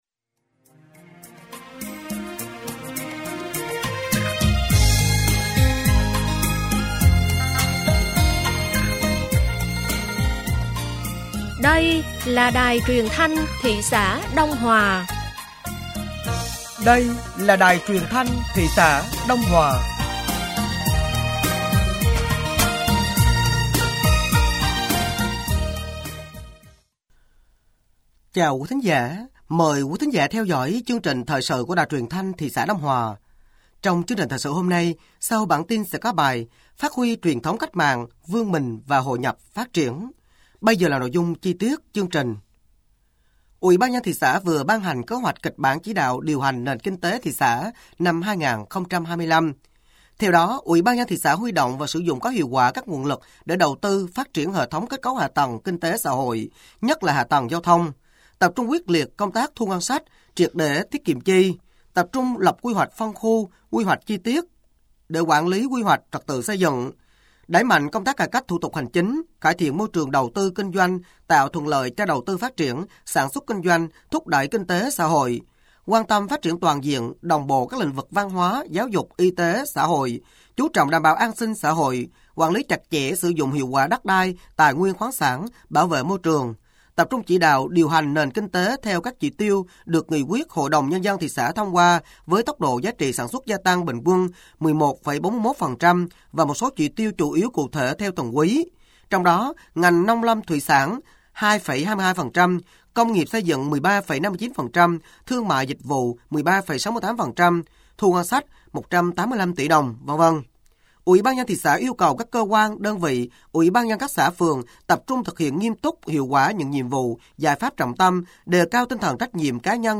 Thời sự tối ngày 03 và sáng ngày 04 tháng 4 năm 2025